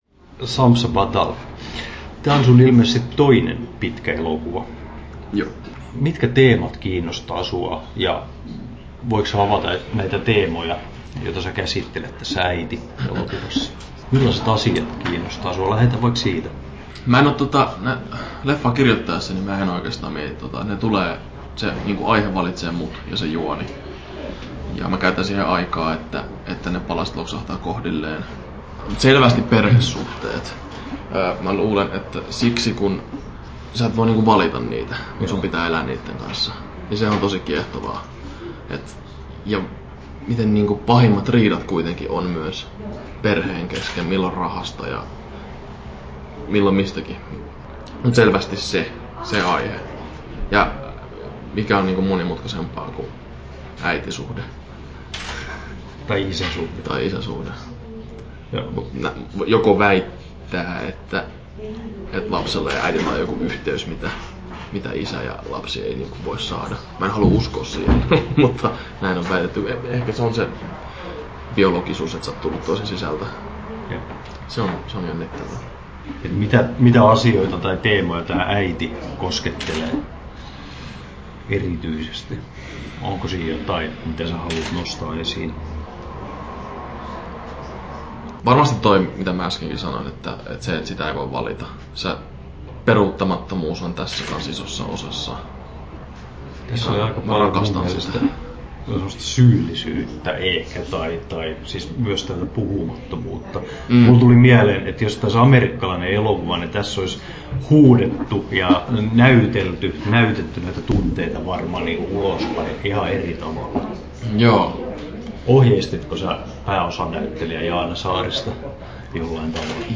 Haastattelussa
9'55" Tallennettu: 25.04.2019, Turku Toimittaja